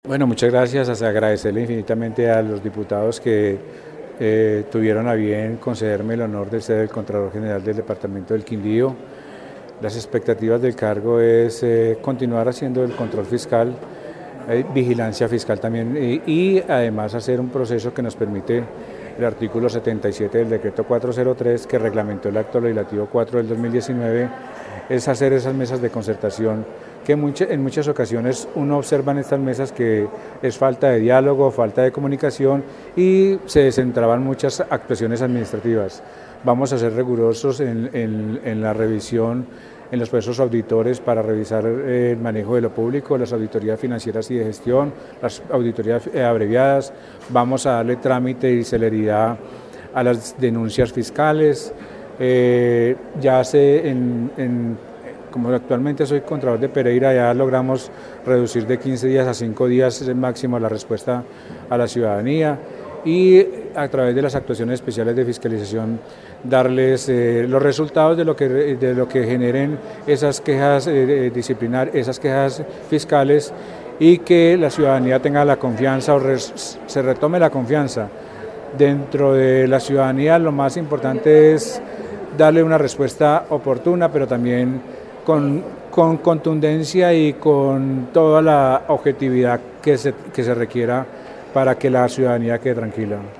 En diálogo con Caracol Radio agradeció el apoyo de la mayoría de los diputados y dijo que dentro de las expectativas es continuar haciendo control fiscal.